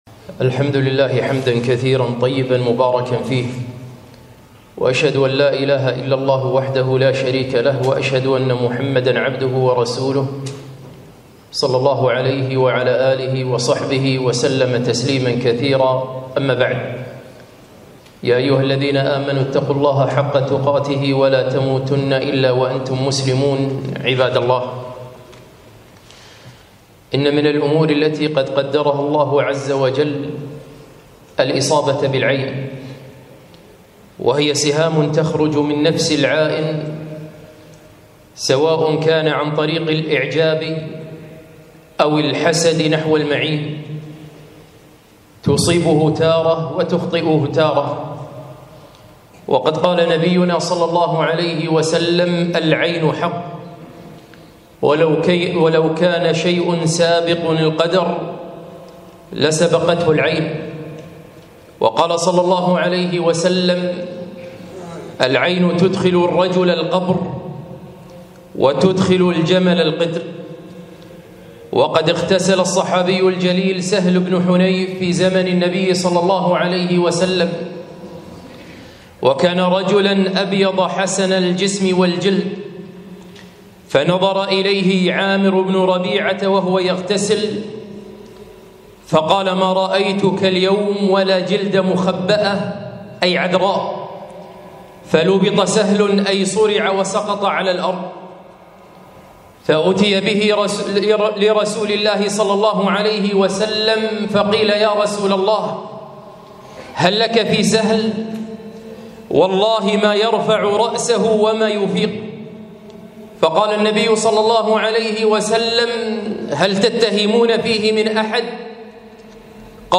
خطبة - الإصابة بالعين